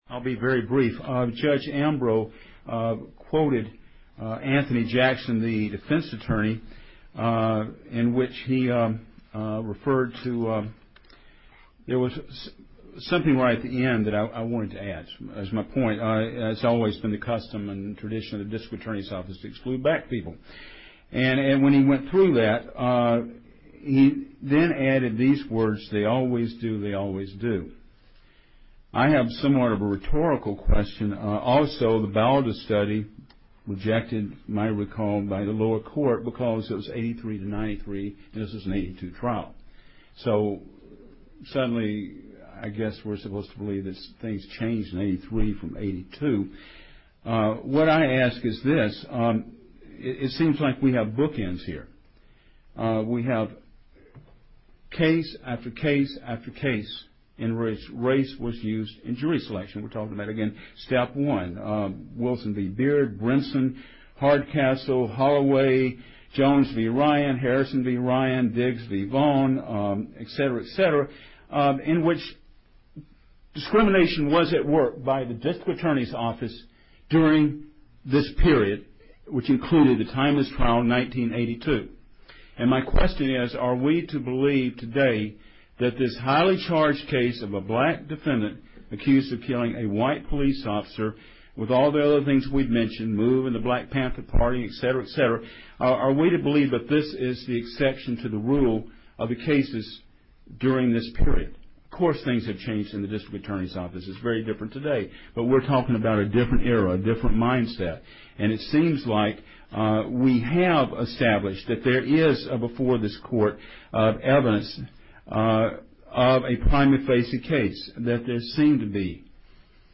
COURTROOM AUDIO: Mumia's May 17 Oral Arguments!
For the first time, Journalists for Mumia presents the courtroom audio from Mumia Abu-Jamal's May 17 oral arguments before the US Third Circuit Court of Appeals.